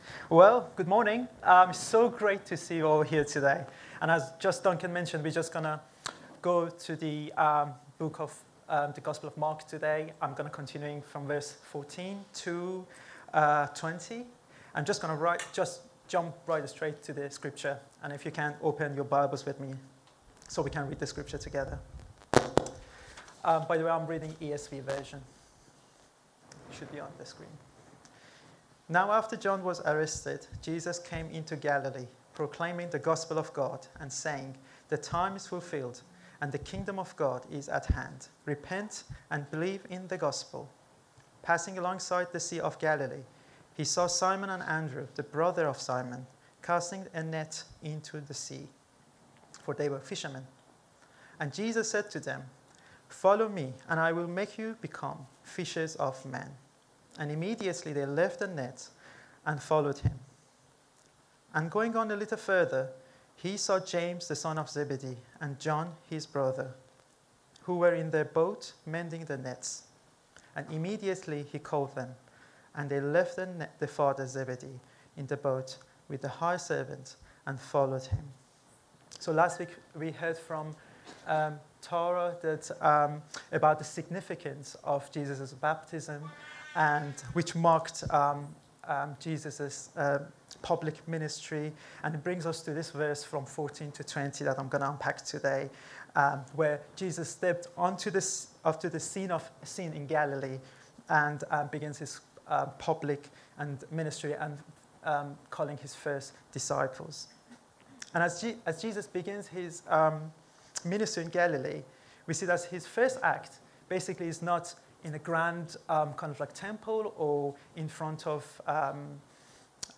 Download The Call And The Answer – First Disciples | Sermons at Trinity Church